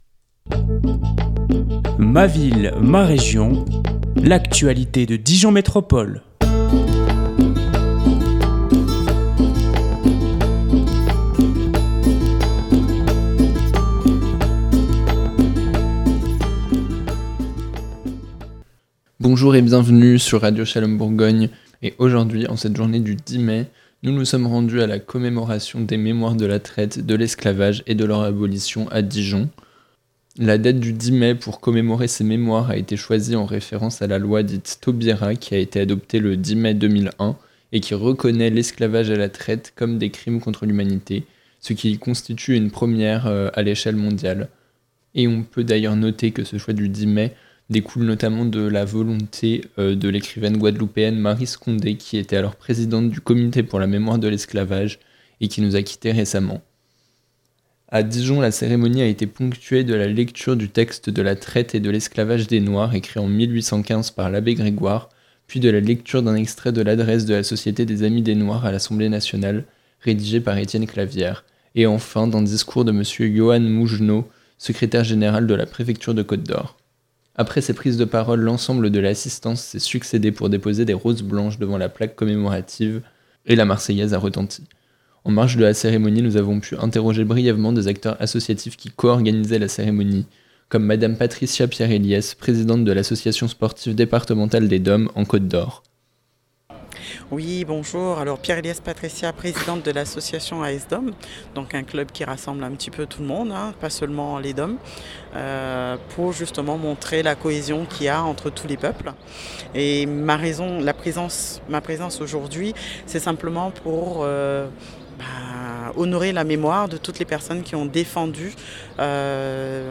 Ce vendredi 10 mai, nous étions au stade Paul Doumer pour la commémoration de la journée des Mémoires de l'esclavage, de la traite et de leur abolition.